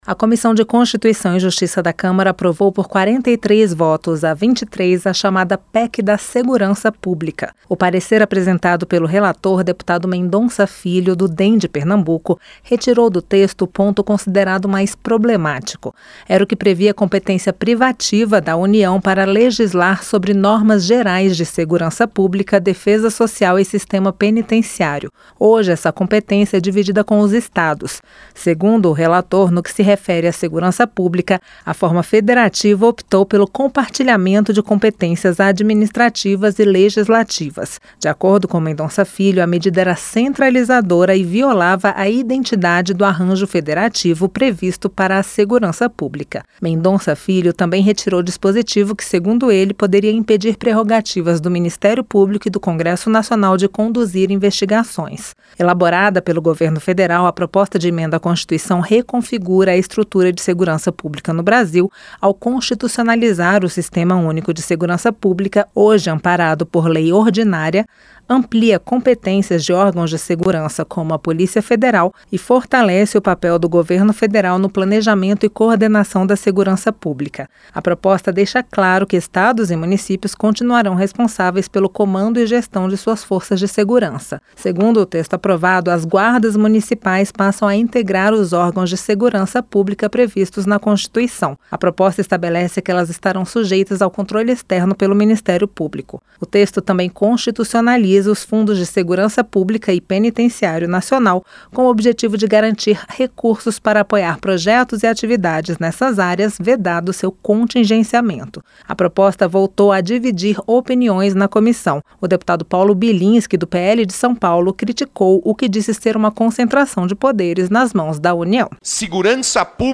• Áudio da matéria